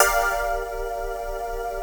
35SYNT01  -L.wav